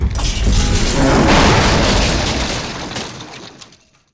can.wav